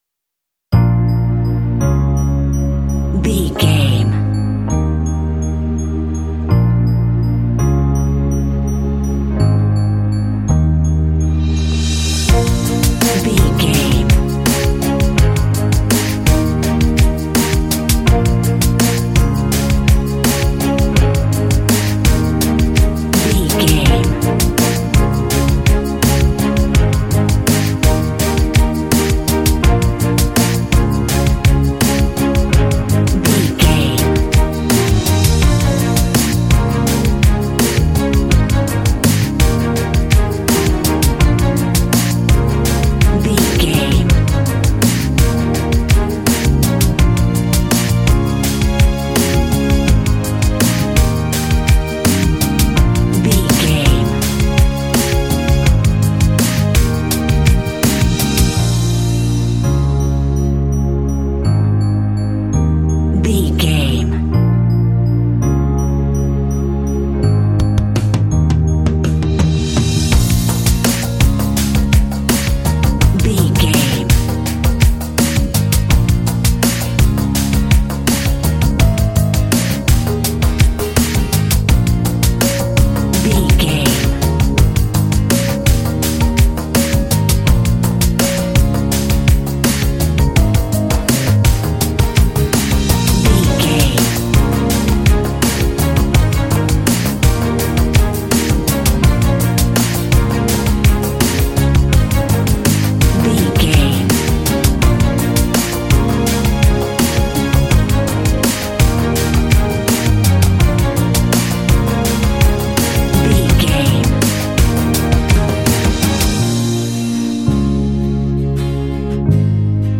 Mixolydian
B♭
fun
bright
lively
sweet
drum machine
piano
strings
synthesiser
acoustic guitar
pop